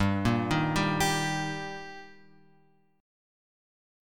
Gm#5 chord {3 1 1 0 x 3} chord